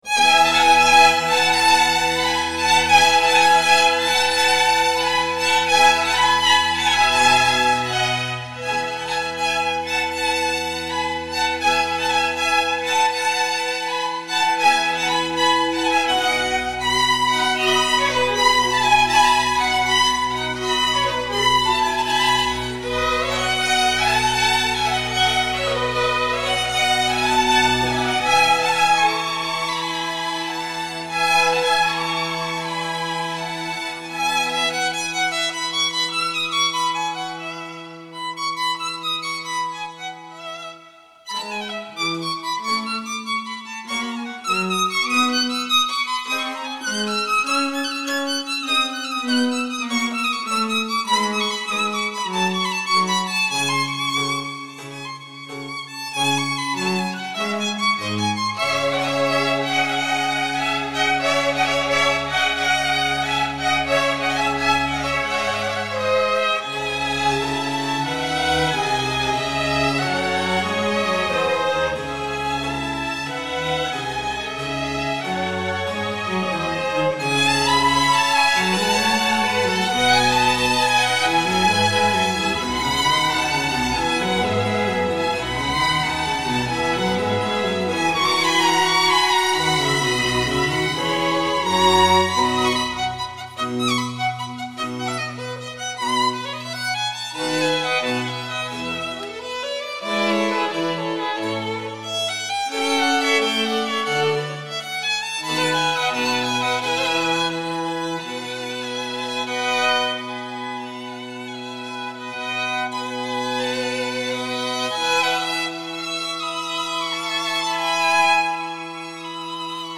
Gedicht aus dem Off
TRACK 1: Unter dem Text läuft eine leichte Soundfläche
Noch erstellen: Text + Soundbett